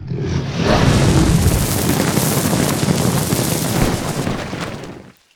fireblow.ogg